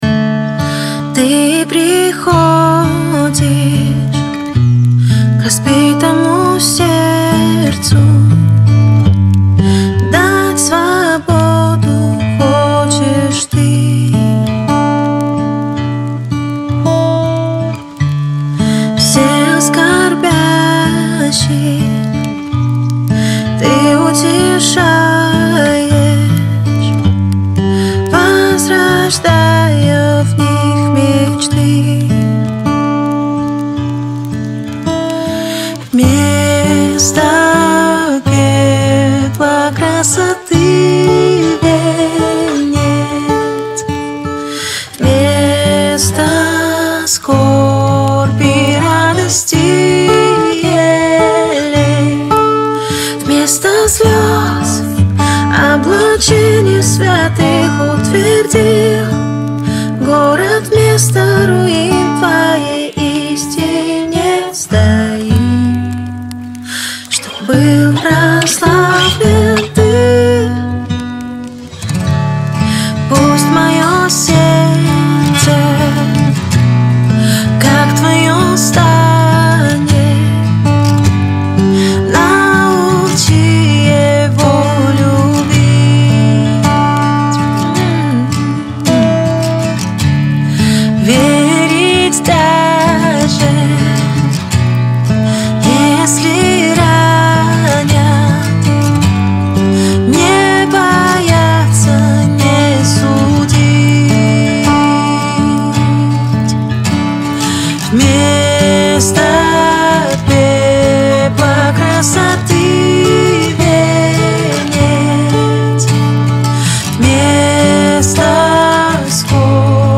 498 просмотров 673 прослушивания 47 скачиваний BPM: 107